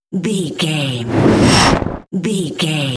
Whoosh fast jet bright
Sound Effects
Fast
futuristic
whoosh